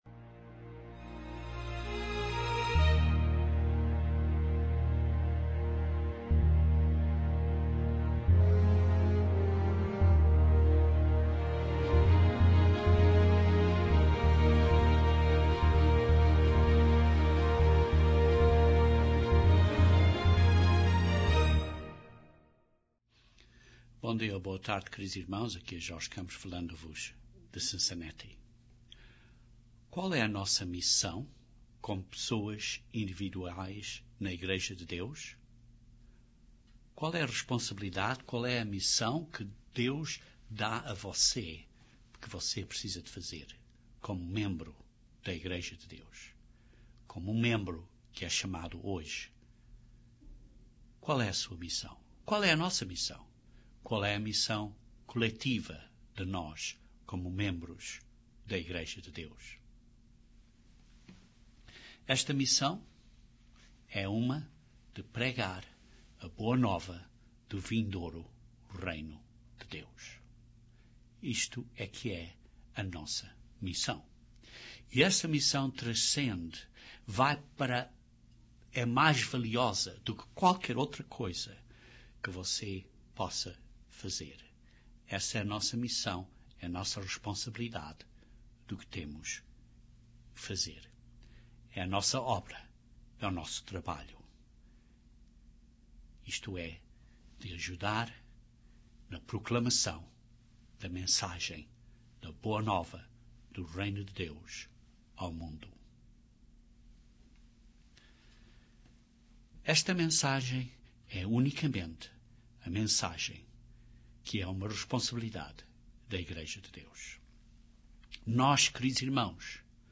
Este sermão descreve essa missão que é a sua, a minha, a nossa, como membros do Corpo de Cristo, isto é, como membros do organismo espiritual que é a Igreja de Deus.